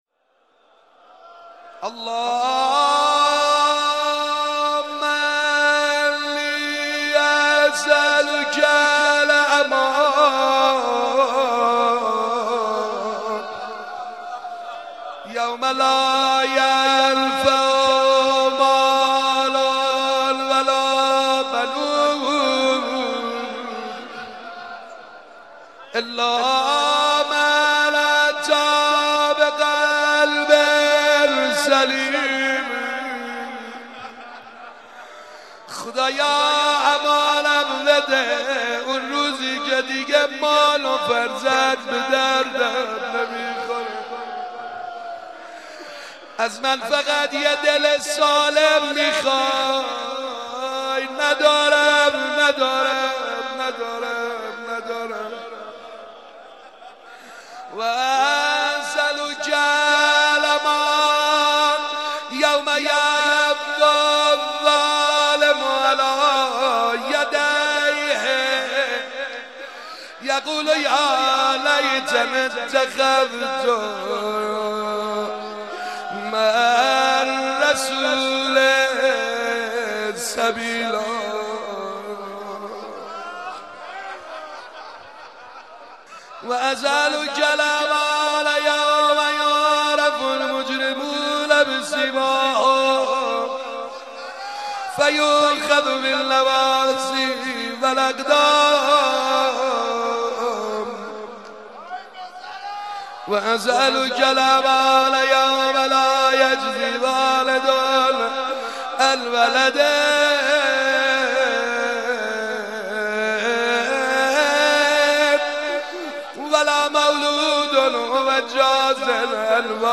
ادعیه مناجاتها